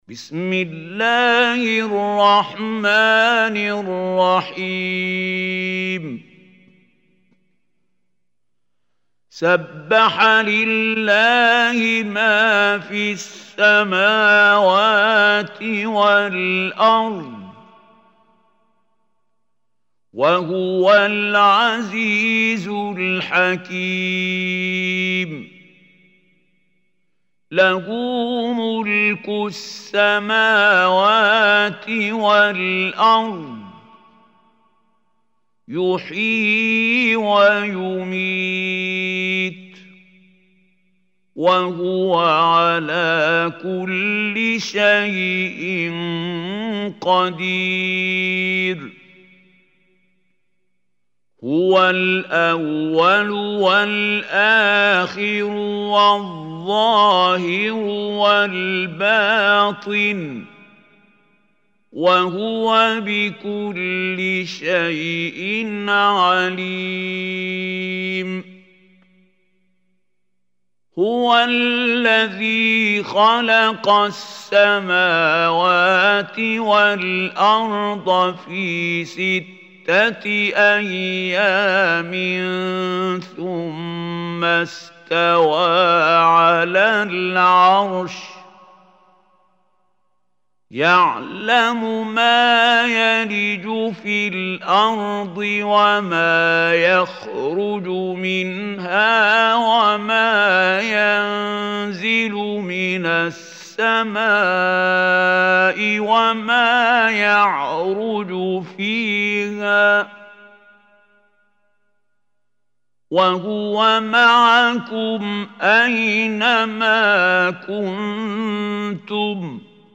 Surah Hadid MP3 Recitation by Khalil Hussary
Surah Hadid is 57 surah of Holy Quran. Listen or play online mp3 tilawat / recitation in the beautiful voice of Mahmoud Khalil Al Hussary.